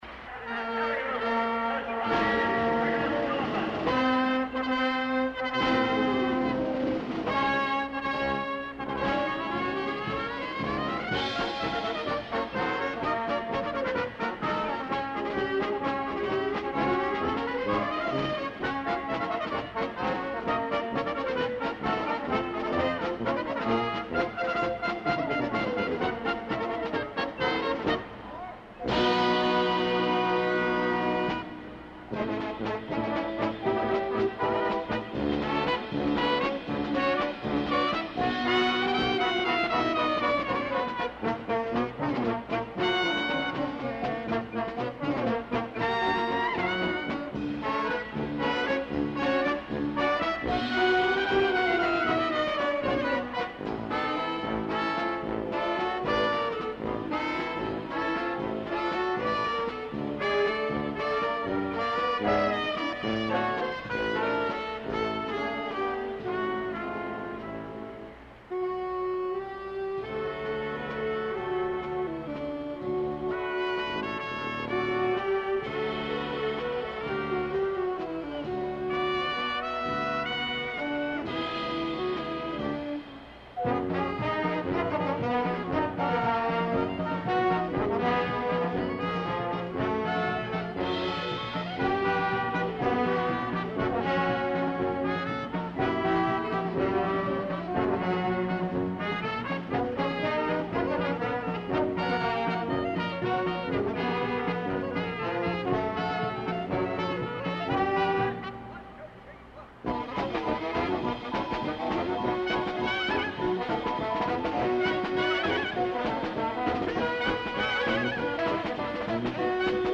The 19th Annual Aladdin Temple Shrine Circus took place in Columbus, Ohio in April 1969 in the Ohio State Fairgrounds coliseum. The first-rate band of local musicians